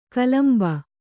♪ kalamba